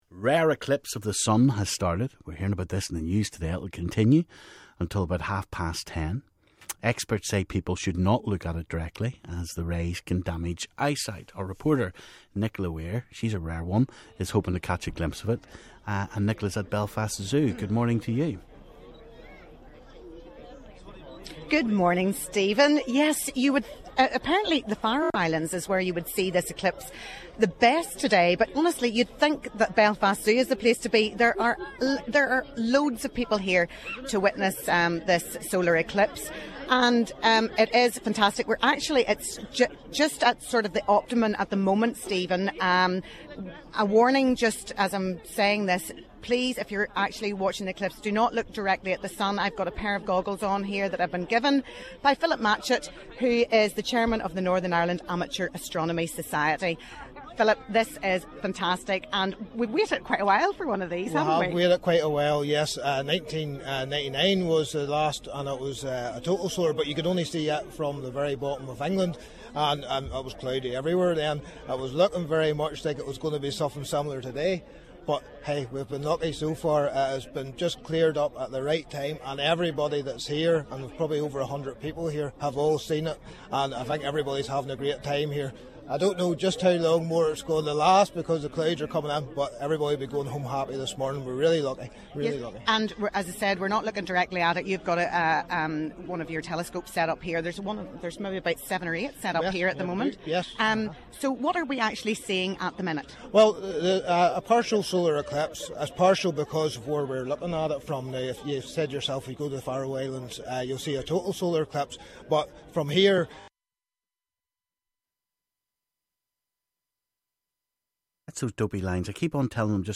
A rare eclipse of the sun while we're on air.